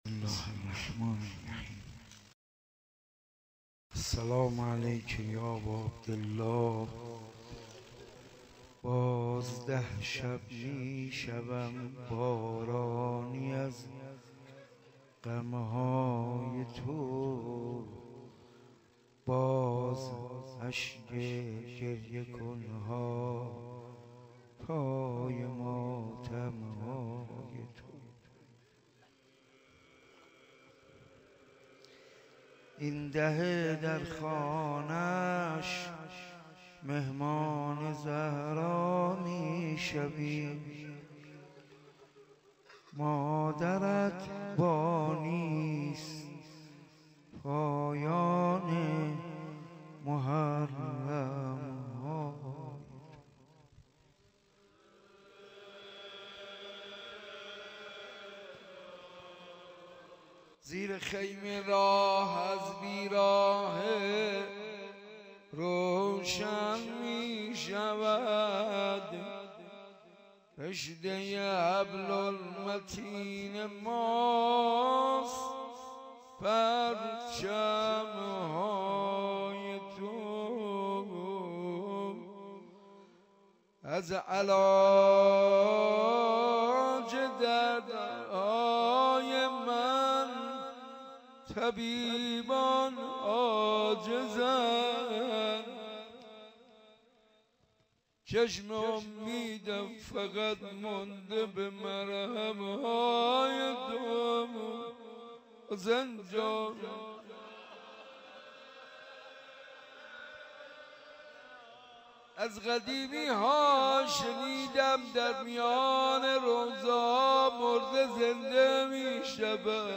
مناسبت : دهه دوم محرم
قالب : مجلس کامل